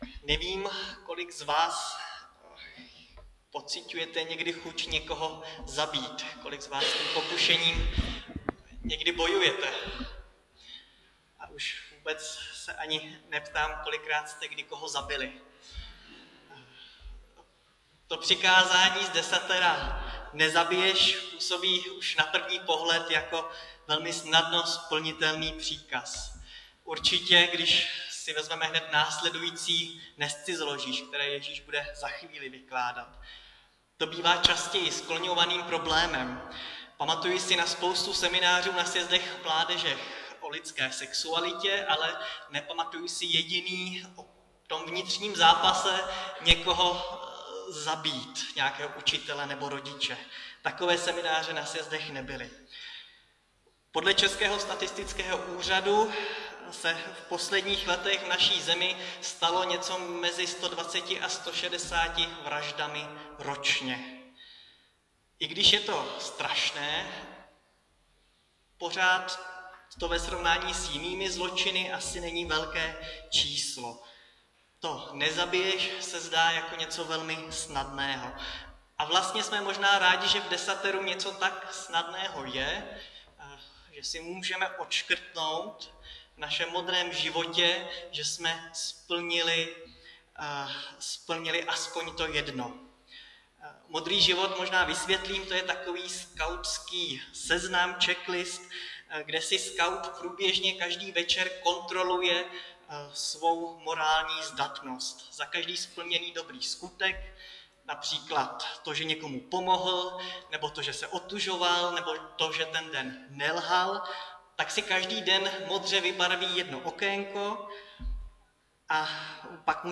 Nedělní kázání 3.10.2021 – Vyšší spravedlnost: Nezabiješ